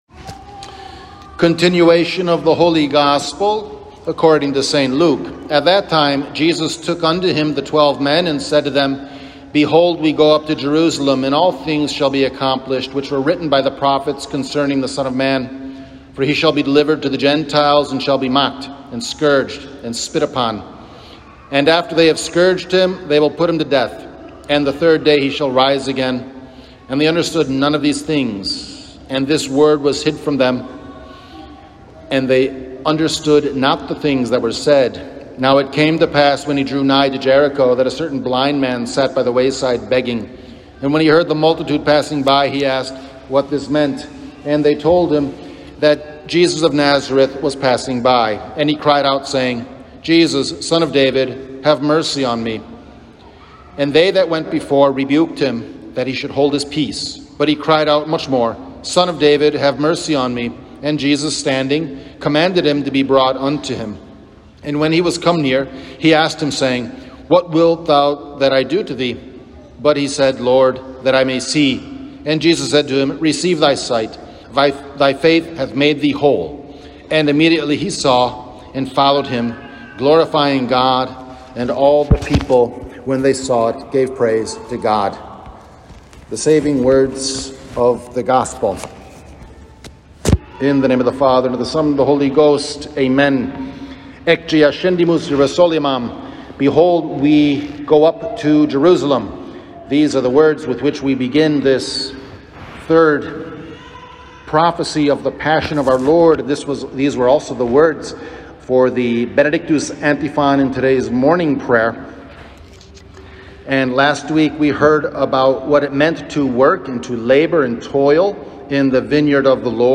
Transcription of Homily